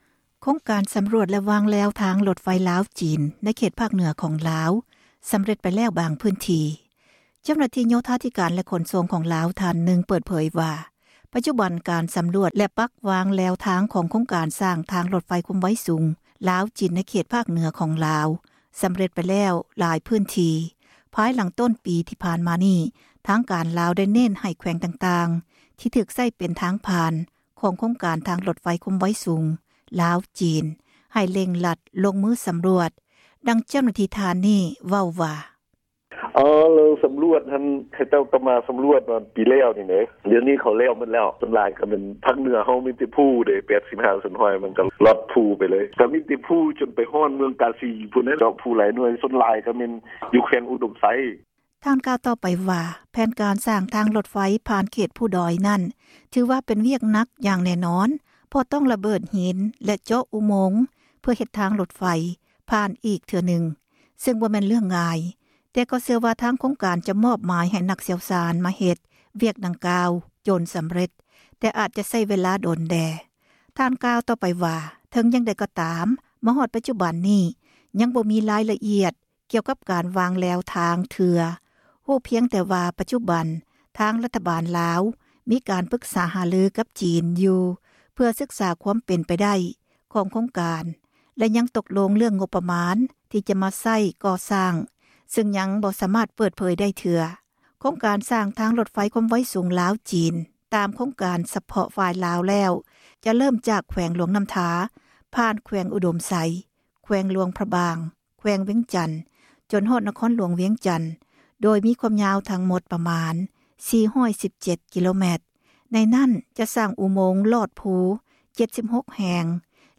ດັ່ງ ເຈົ້າໜ້າທີ່ ເວົ້າວ່າ: